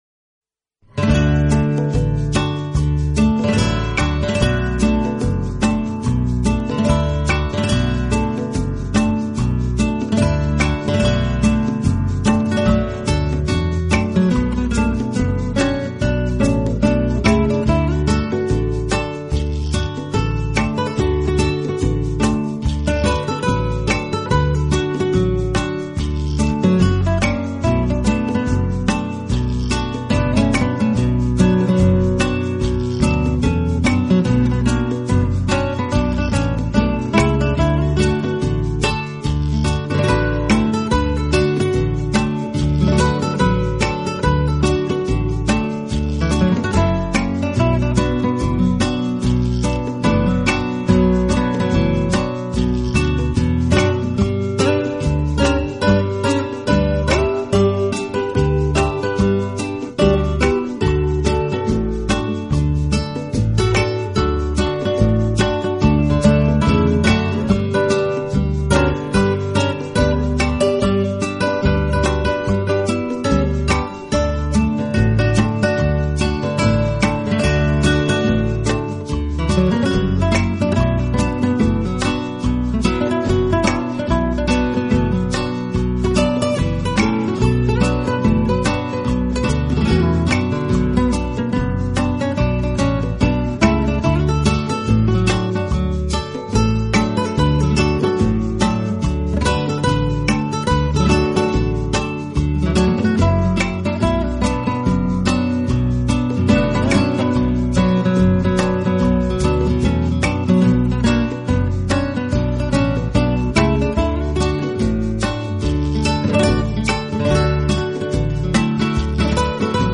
音乐类型：Instrumental
浪漫吉他曲，经典西洋乐，音符似跳动的精灵，释放沉睡已久的浪漫情怀，用吉他的清脆表